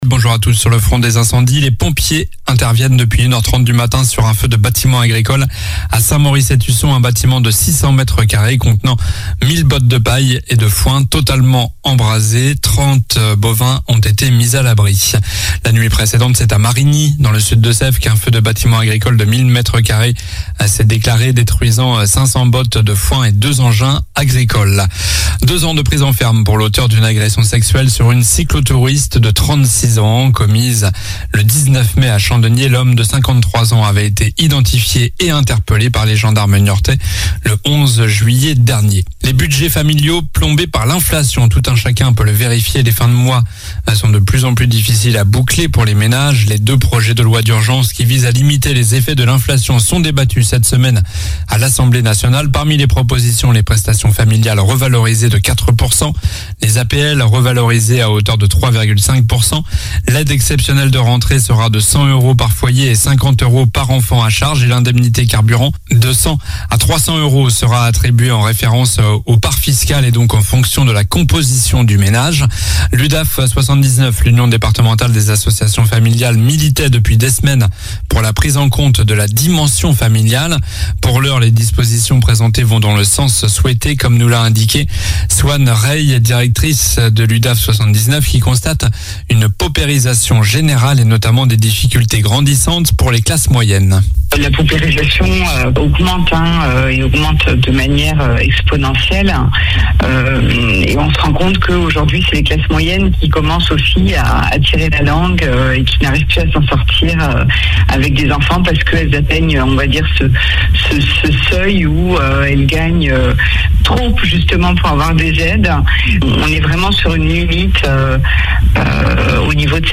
Journal du mercredi 20 juillet (matin)